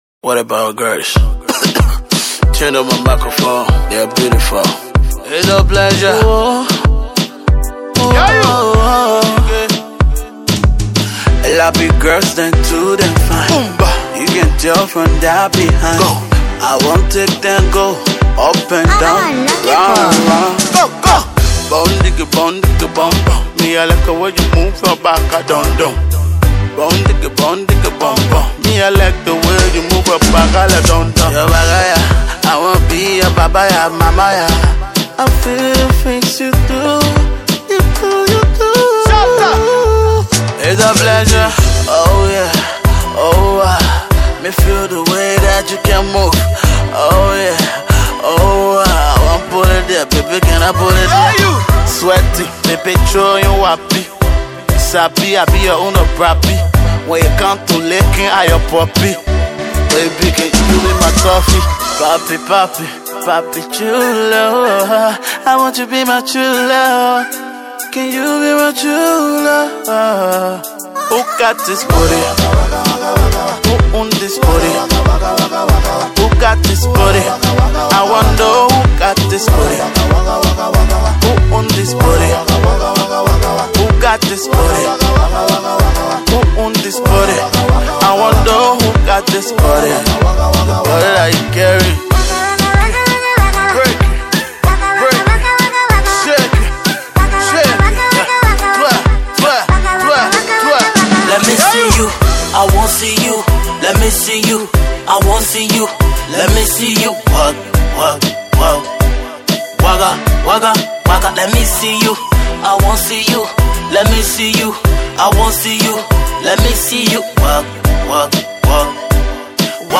New Dancehall thing